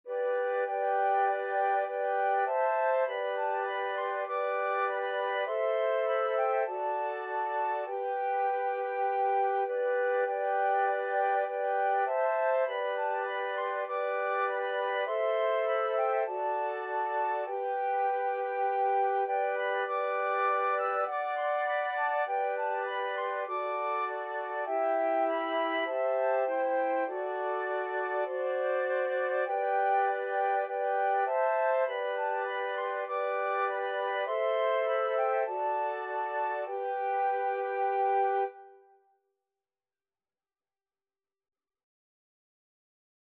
Christmas Christmas Flute Quartet Sheet Music O Little Town of Bethlehem
G major (Sounding Pitch) (View more G major Music for Flute Quartet )
4/4 (View more 4/4 Music)
Flute Quartet  (View more Easy Flute Quartet Music)
Traditional (View more Traditional Flute Quartet Music)
o_little_town_4FL.mp3